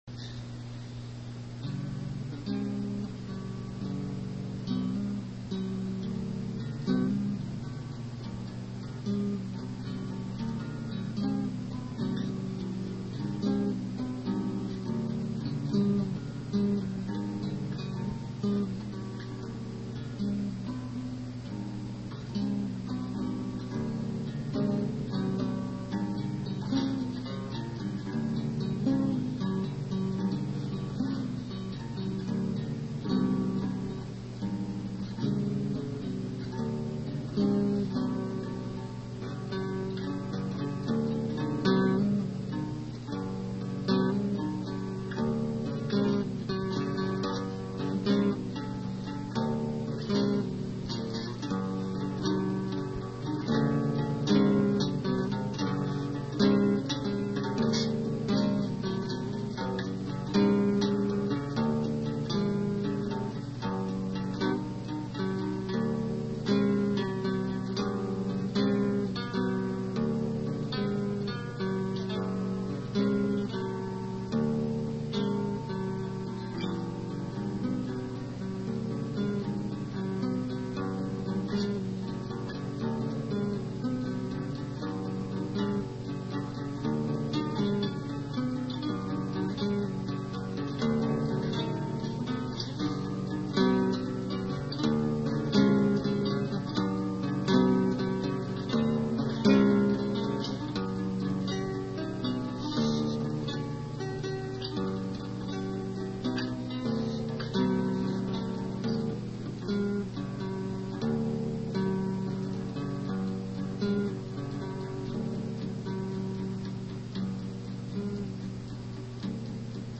on guitar